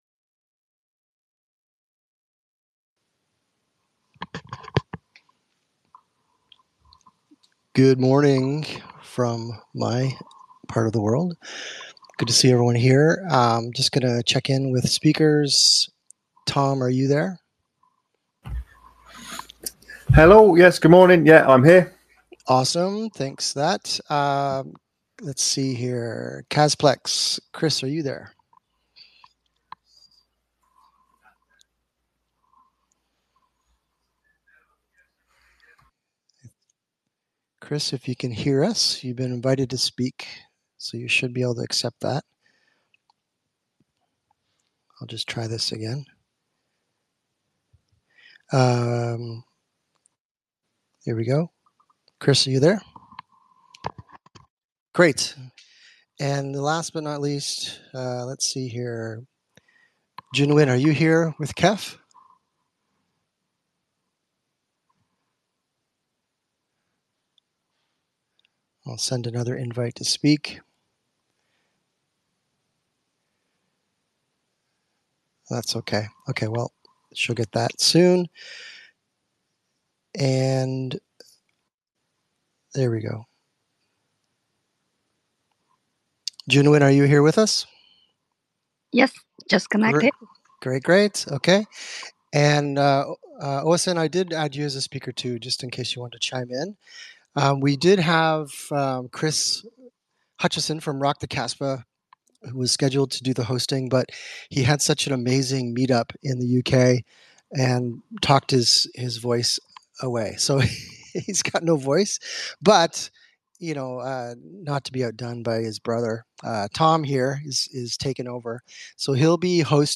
MC Questions